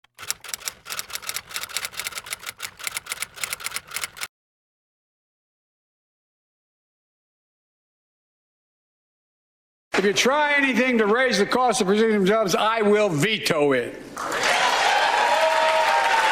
"President" Brandon gets standing ovation for gibberish